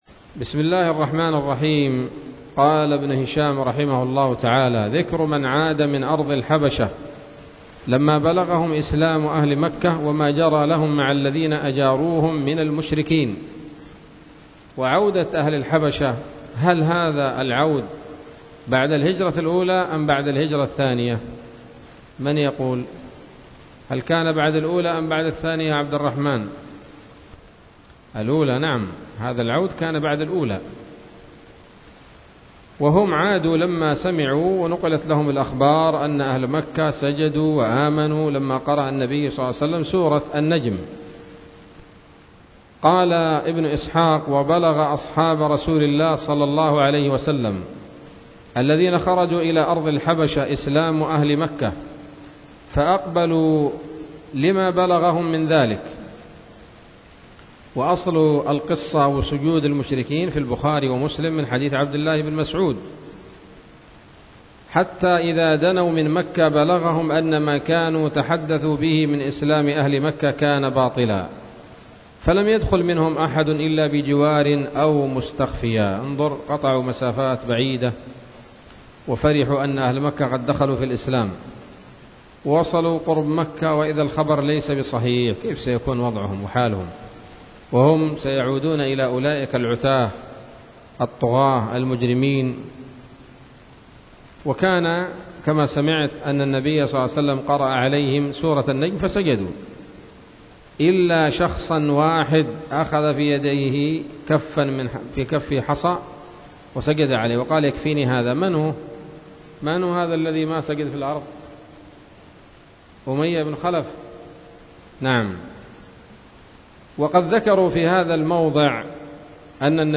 الدرس التاسع والثلاثون من التعليق على كتاب السيرة النبوية لابن هشام